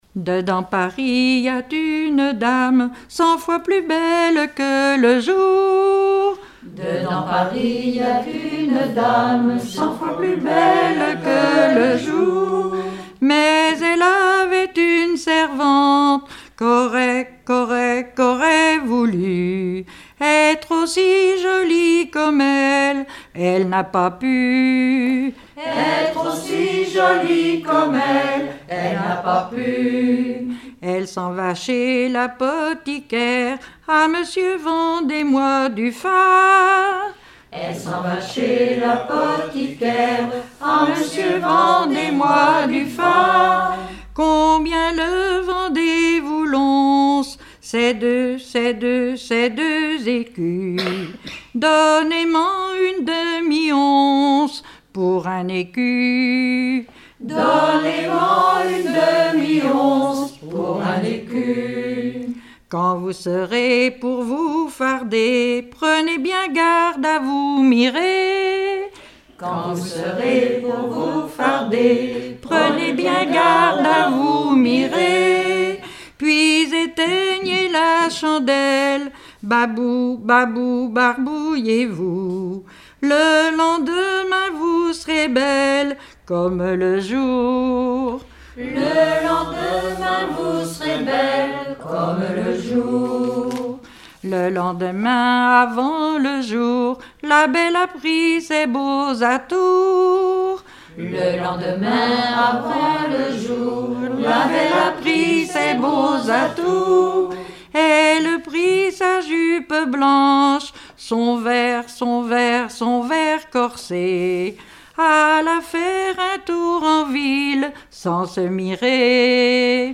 Rassemblement de chanteurs
Pièce musicale inédite